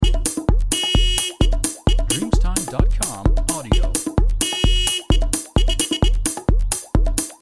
Verrückte Auto-Hupenen-Haus-Schleife